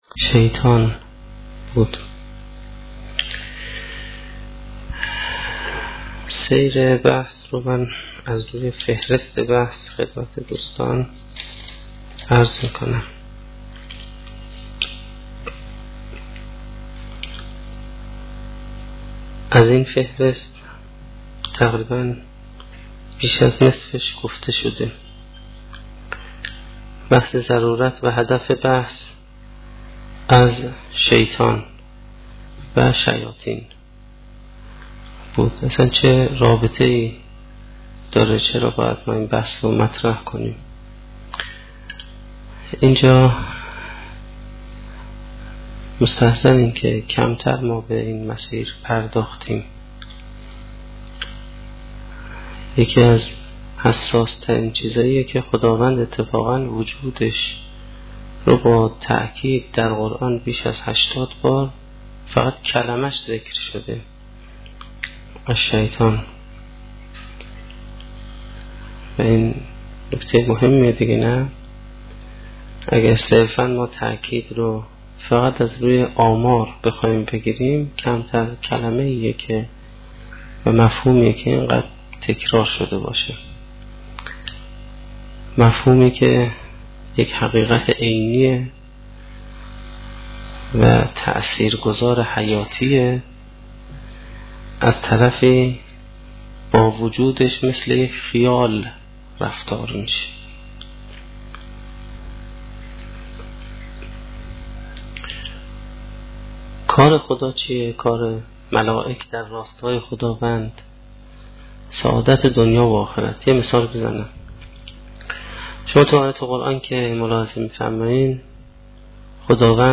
سخنرانی اولین شب دهه محرم1435-1392